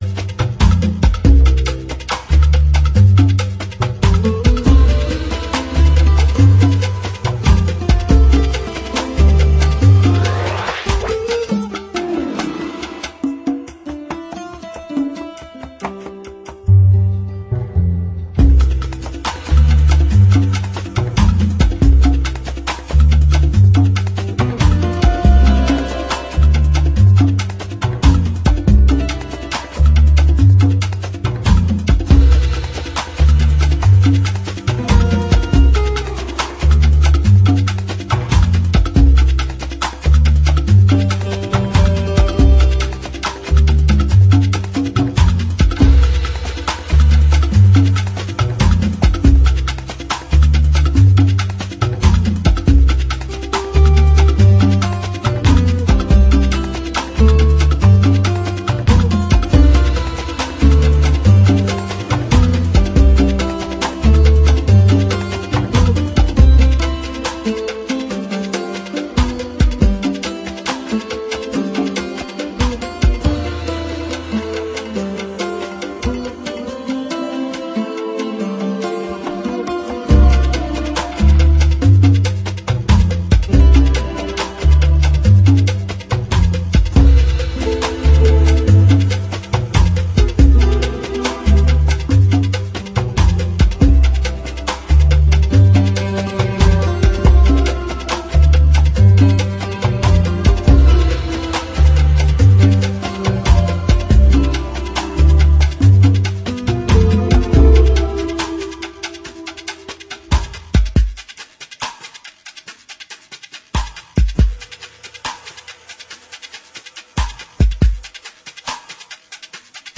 Electronix Bass